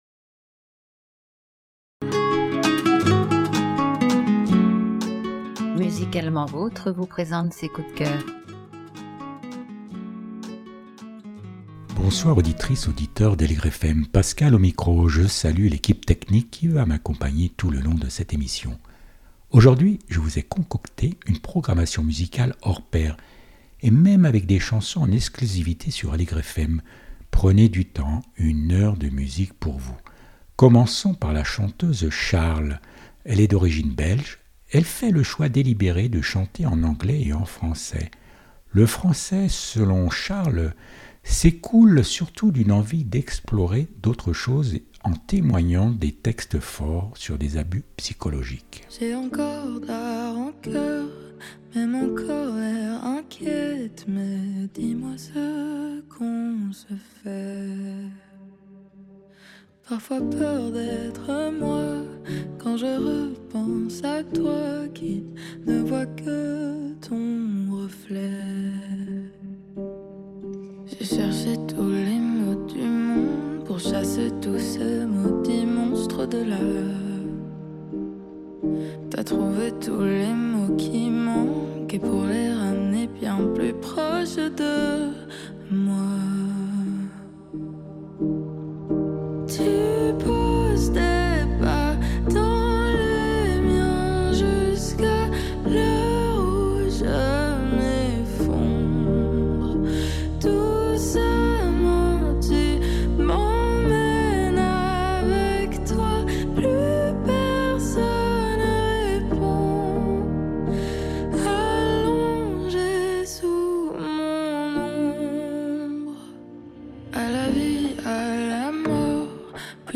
Les portraits radiophoniques du 1 AVRIL 2025